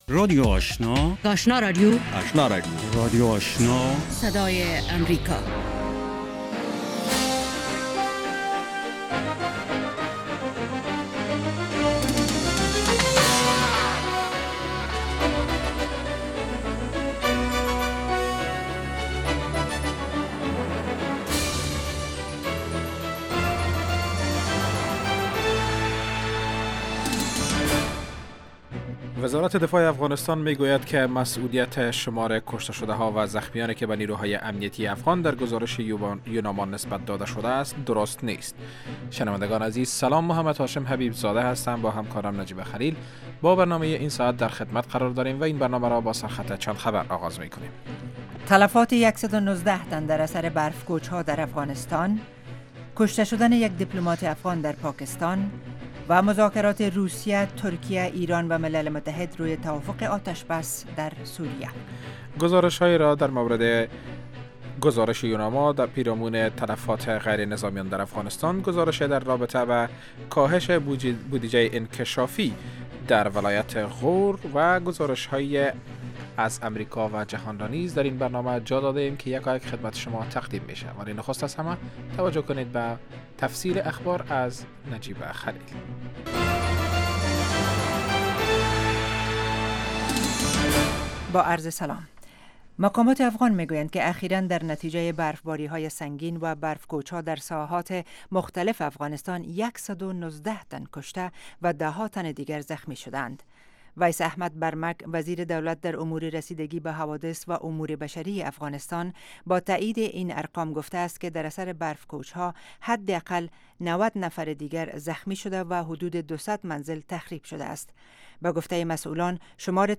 در اولین برنامه خبری شب، خبرهای تازه و گزارش های دقیق از سرتاسر افغانستان، منطقه و جهان فقط در سی دقیقه.